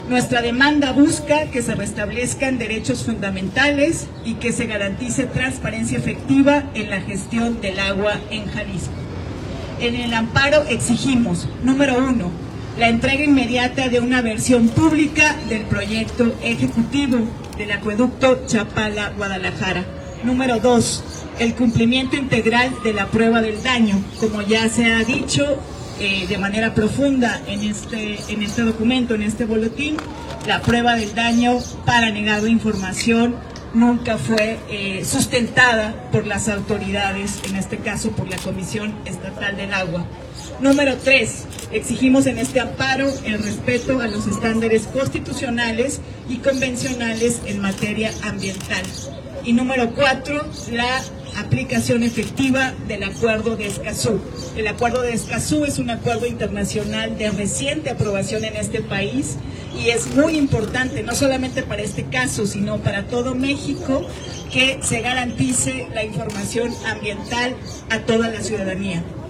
Este 18 de febrero en conferencia de prensa en la Plaza de Armas de la ciudad de Guadalajara; diferentes colectivos se pronunciaron para denunciar la opacidad en el tratamiento de la información por parte de la Comisión Estatal del Agua sobre el Nuevo Acueducto Chapala- Guadalajara.